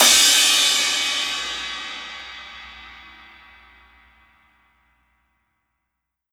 Crashes & Cymbals